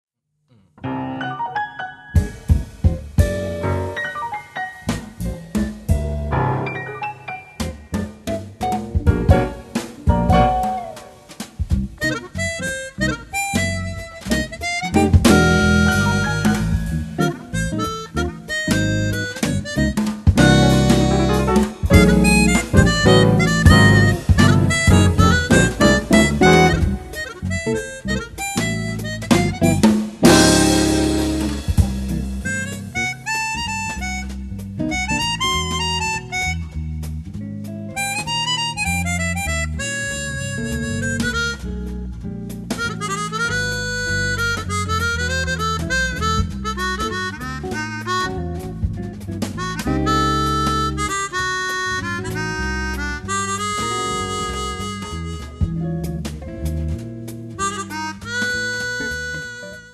armonica
basso
chitarra
piano
batteria
più tradizionale e divertente